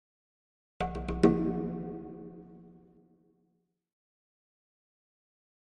Jungle Drums Triple Hits Version 2 - Stronger Final